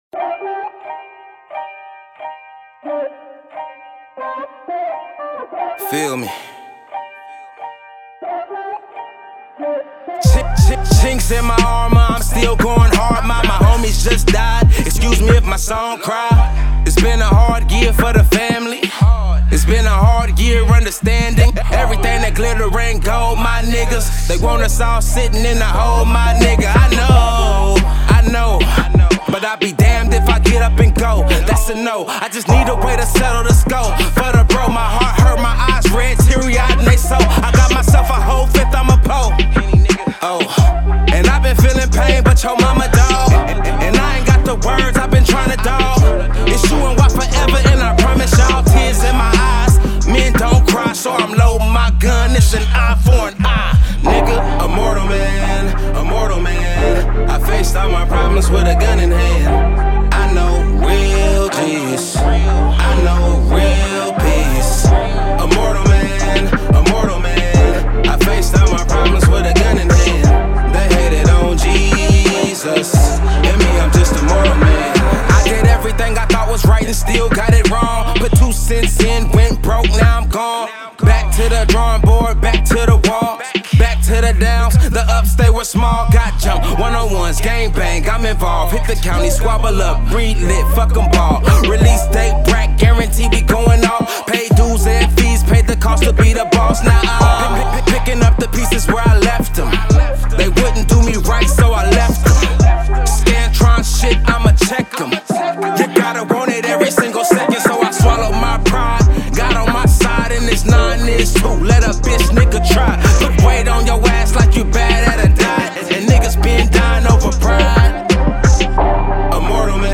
Hiphop
heartfelt street banger